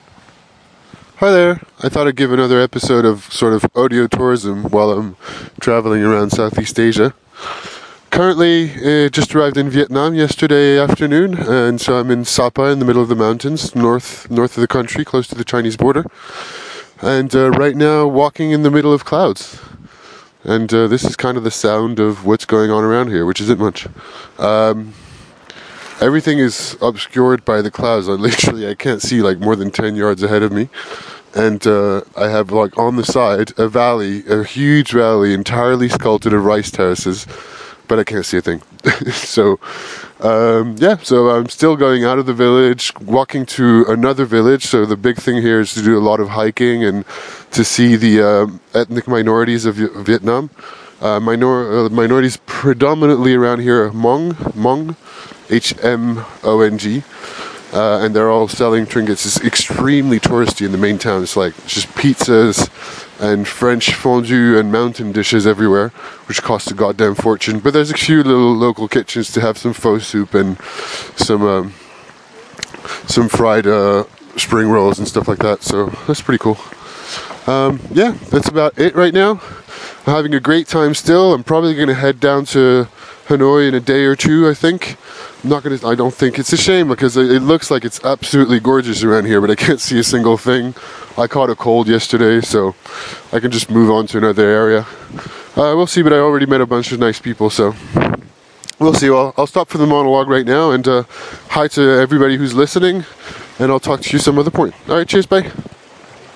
Next Some civilised live jazz in an otherwise chaotic city (Bangkok).